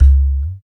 80 TLK DRM-L.wav